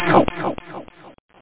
ouch.mp3